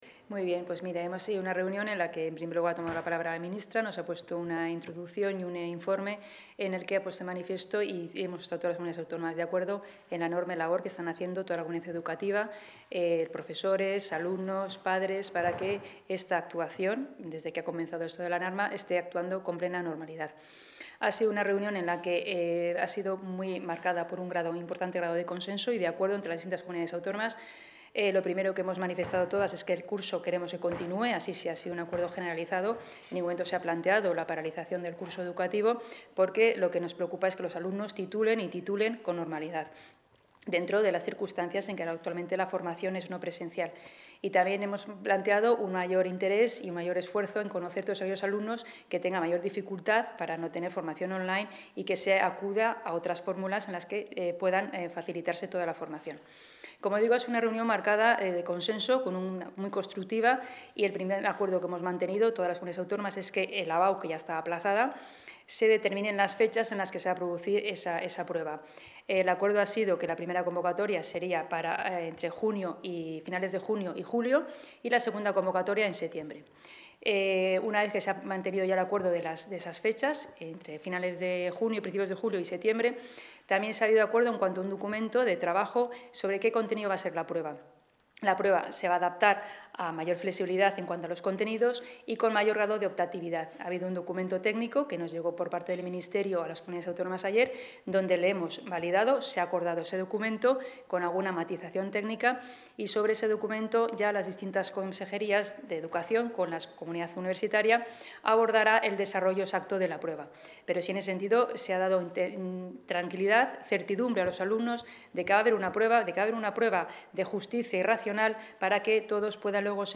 Declaraciones de la consejera.